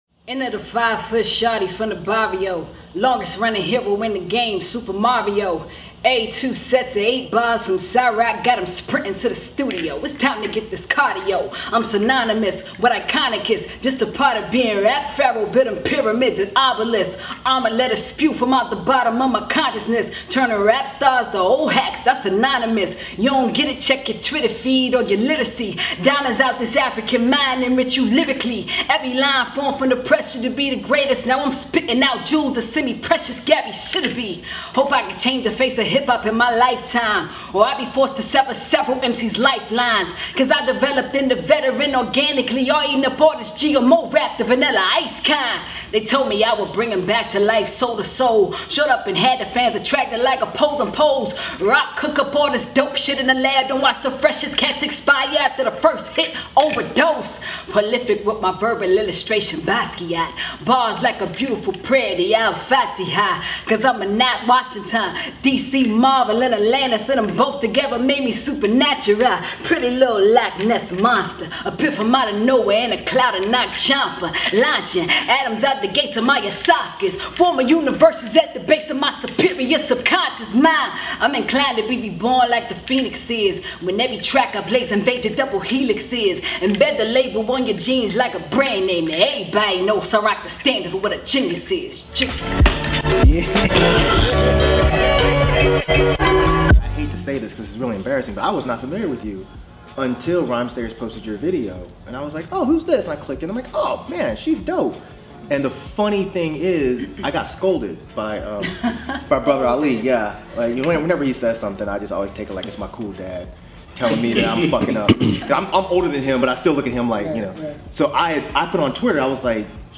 DEHH Interview